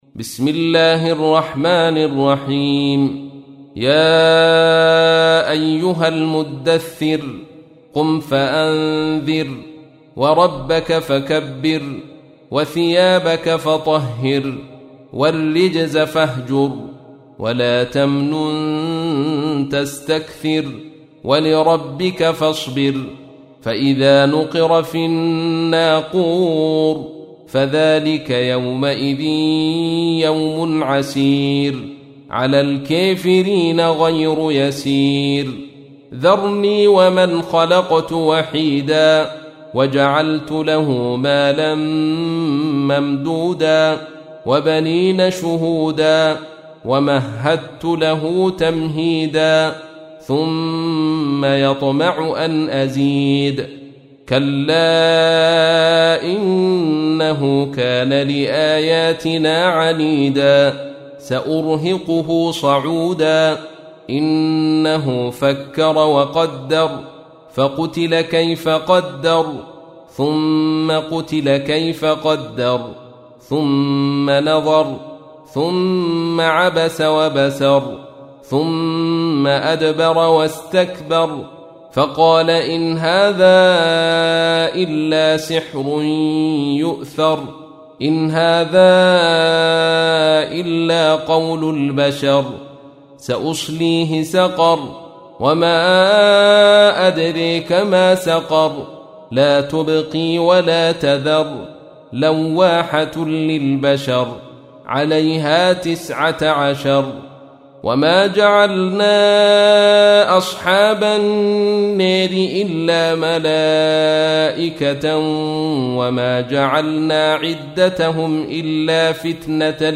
تحميل : 74. سورة المدثر / القارئ عبد الرشيد صوفي / القرآن الكريم / موقع يا حسين